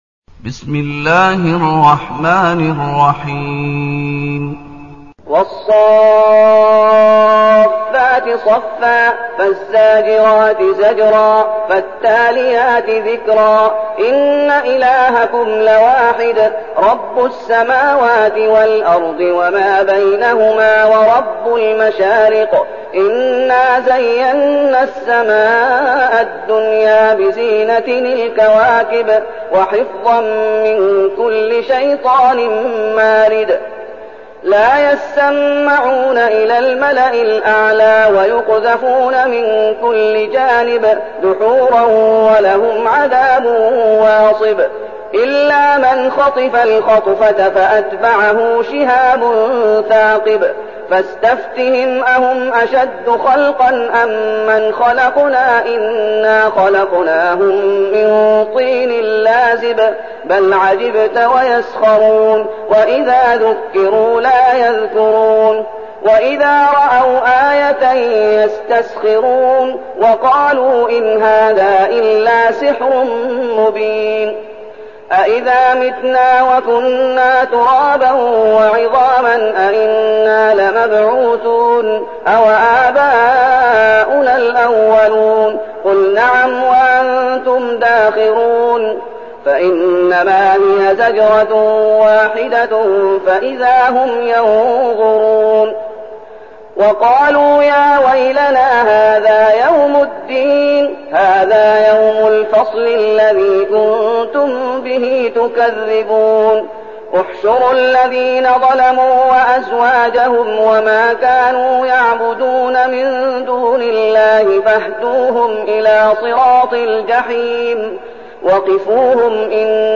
المكان: المسجد النبوي الشيخ: فضيلة الشيخ محمد أيوب فضيلة الشيخ محمد أيوب الصافات